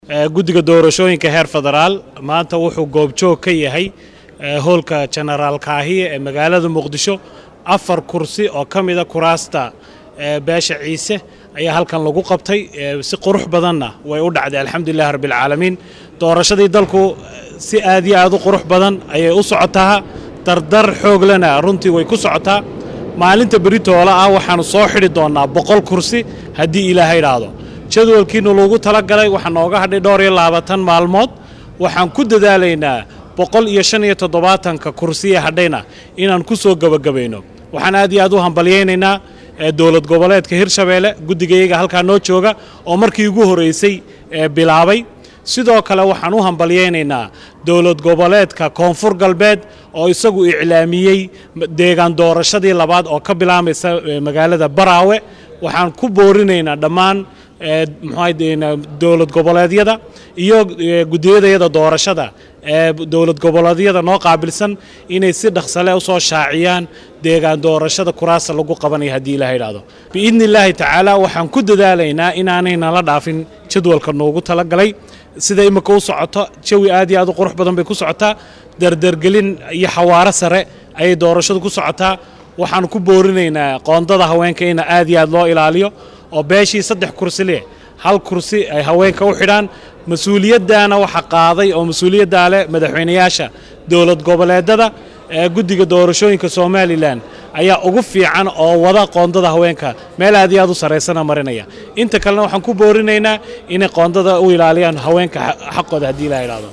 Xilli uu Warbaahinta kula hadlay Gudaha Dugsiga tababarka Booliska ee Janeraal Kaahiye ee magaalada Muqdisho oo Maanta lagu qabtay doorashada Afar Kursi ayuu sheegay in si dardar leh ay ku socota doorashada ayna rajo ka qabaan in jadwalkii loo asteeyay ee 25-ka Bishan in lagu soo gabagabeeyo.